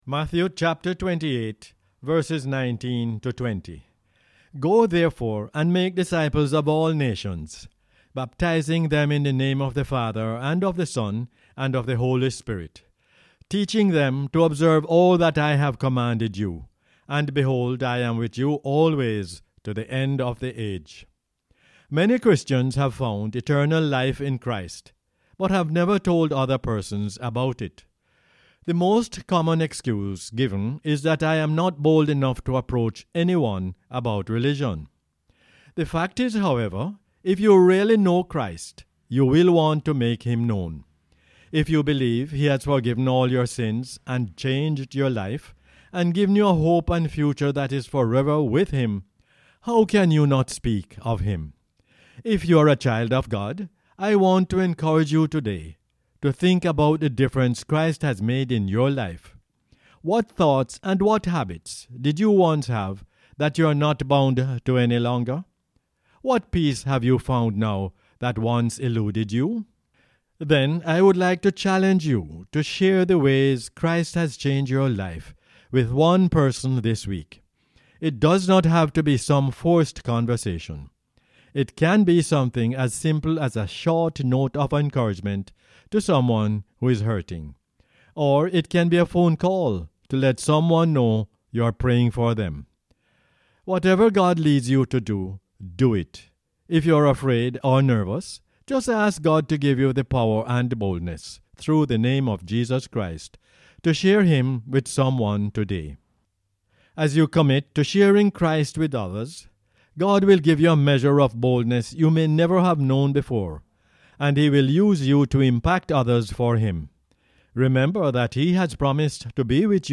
Matthew 28:19-20 is the "Word For Jamaica" as aired on the radio on 9 April 2021.